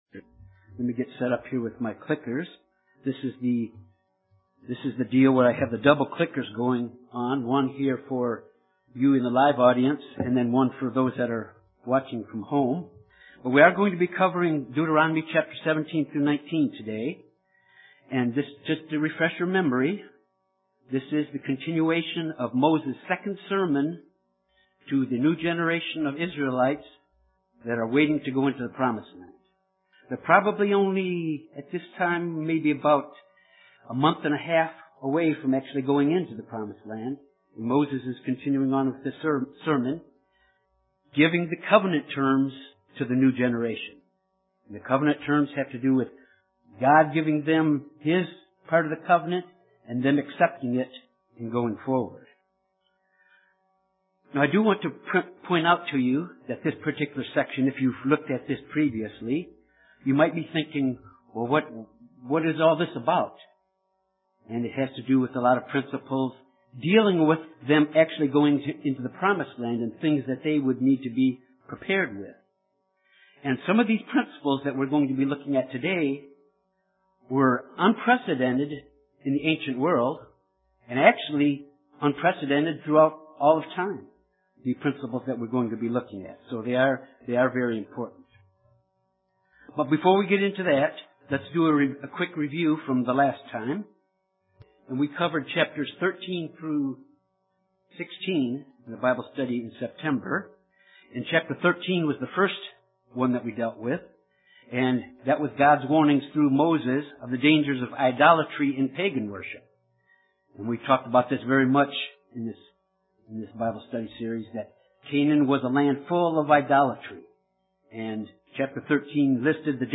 This Bible study covers Deuteronomy 17-19, which is a continuation of Moses’ second sermon to the new generation of the Children of Israel just before they enter into the Promised Land. In these chapters, Moses warns the Children of Israel concerning idolaters and obedience to authority and kings.
Given in Jonesboro, AR Little Rock, AR Memphis, TN